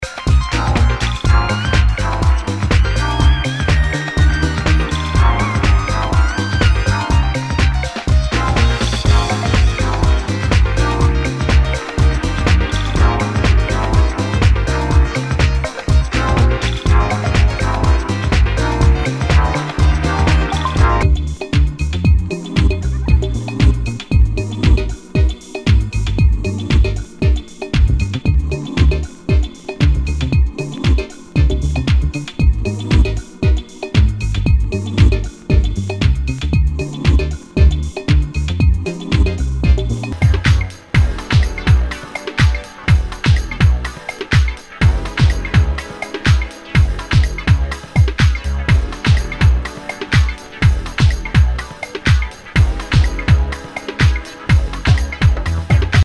HOUSE/TECHNO/ELECTRO
ナイス！ディープ・ハウス！！